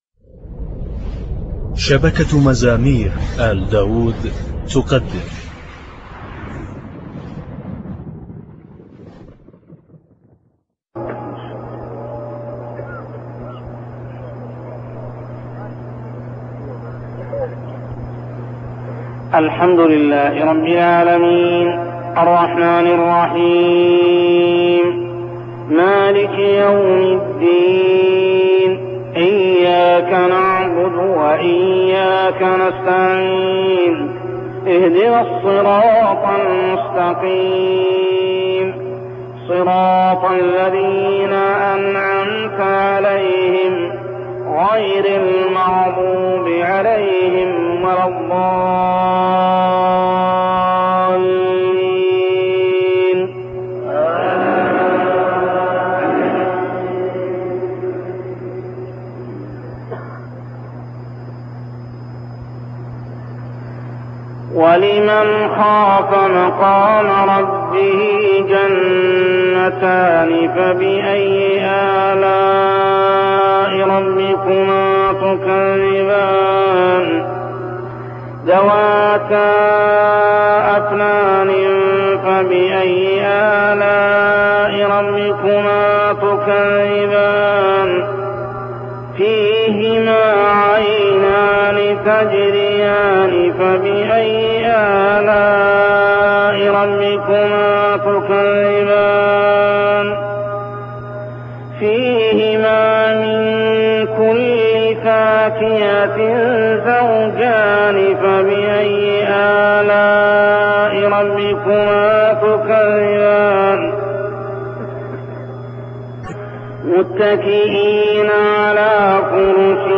صلوات الفروض من الحرم المكي 1402هـ
• الرواية : حفص عن عاصم